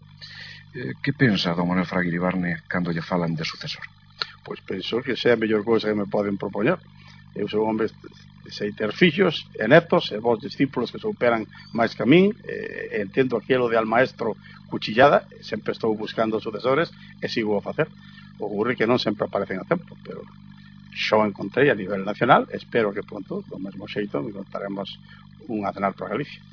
São de uma entrevista feita por Fernando Ónega na TVG, 31 Julho 1990.